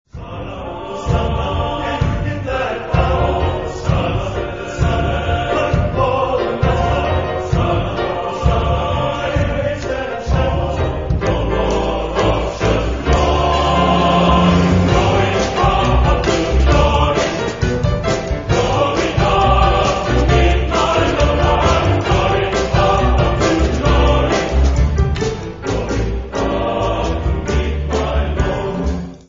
Genre-Style-Form: Sacred ; Work song ; Spiritual ; Gospel
Mood of the piece: rhythmic
Type of Choir: TTBB  (4 men voices )
Instruments: Piano (1)
Tonality: G minor
Musicological Sources: Traditional field holler/work song